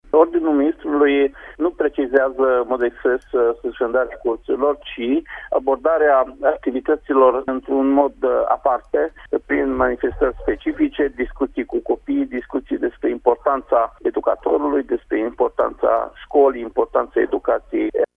Ziua va fi marcată și în școlile din județul Mureș, spune inspectorul școlar general al județului Mureș, Ștefan Someșan: